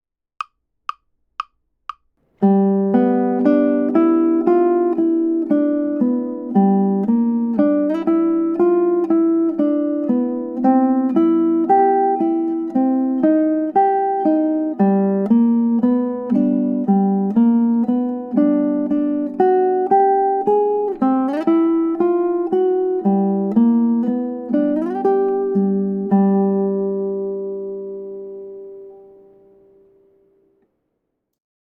The challenge of the melody is mostly about getting used to the new symbols and the sound of chromatic notes (notes out of the key).
Melody only (no chords)
accidental_blues_melody.mp3